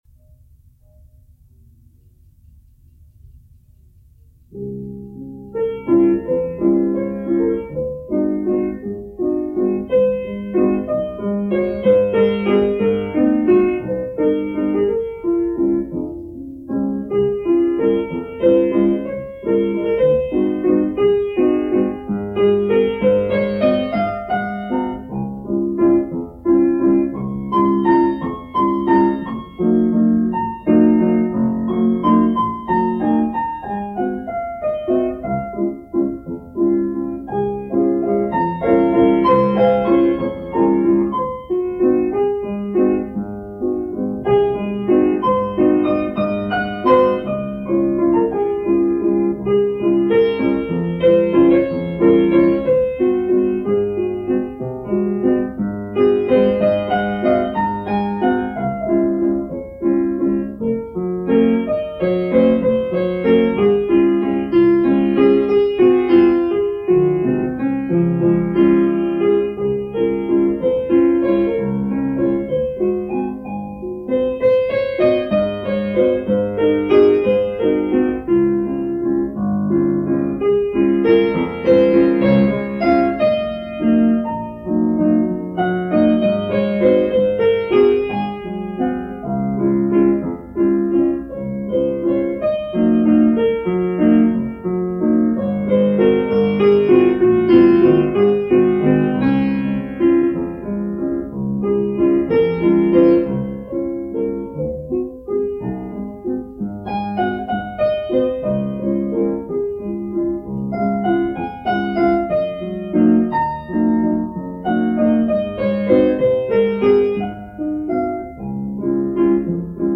instant piano improvisations